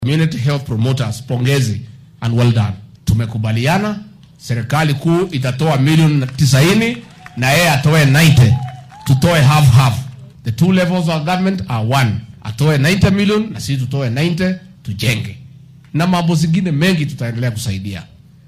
Gachagua ayaa xusay in tani ay gacan ka gaysan doonto in xilli hore laga war helo cudurrada si hoos loogu dhigo qarashaadka ku baxa daaweynta xanuunnada. Madaxweyne ku xigeenka dalka ayaa xilli uu ku sugnaa deegaanka Nyahururu ee ismaamulka Laikipia sheegay in dowladda dhexe ay bixisay lacag bil kasta loogu tala galay in ay qaataan shakhsiyaadka ka shaqeeya daryeelka bulshada oo guud ahaan wadanka ay tiradoodu tahay 107,000.